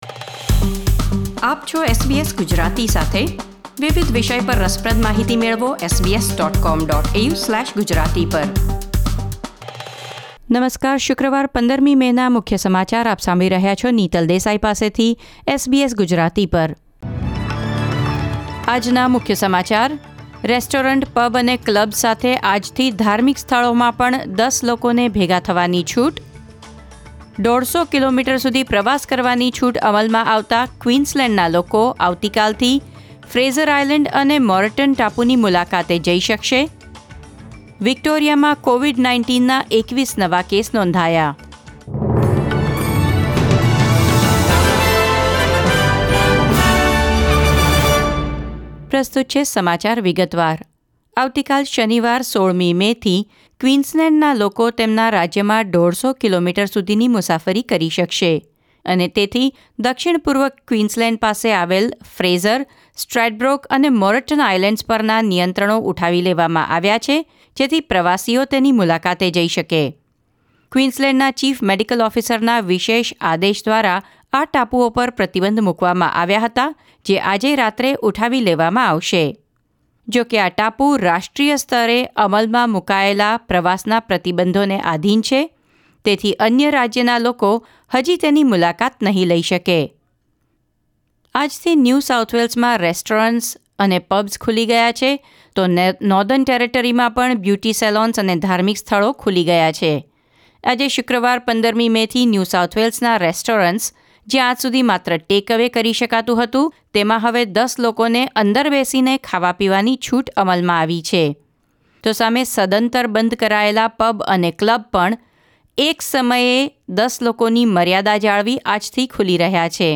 SBS Gujarati News Bulletin 15 May 2020